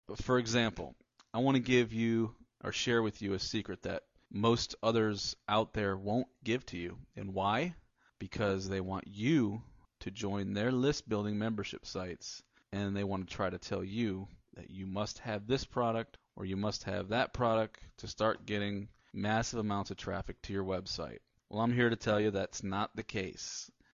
Traffic Building Success Audiobook Resale Rights